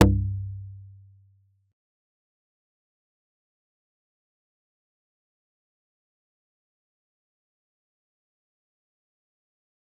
G_Kalimba-C2-pp.wav